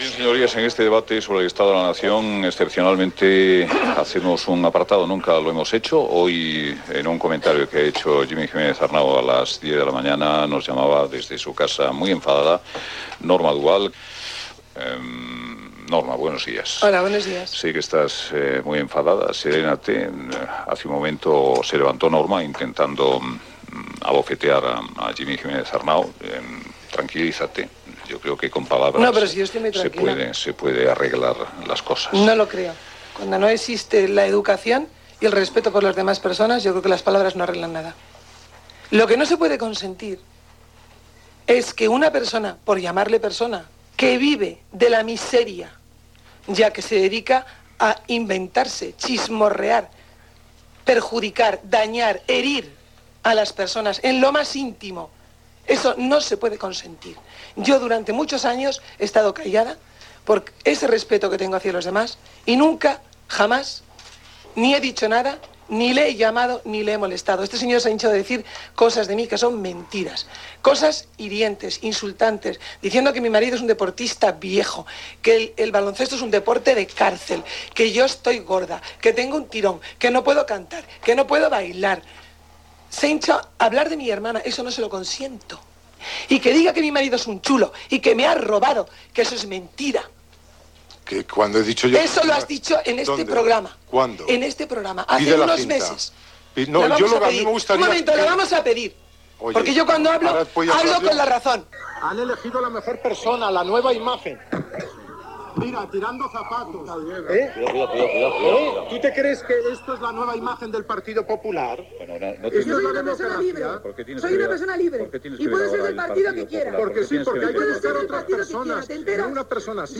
Discusió entre la vedette Norma Duval i el periodista del cor Jimmy Giménez Arnau, col·laborador del programa
Info-entreteniment
FM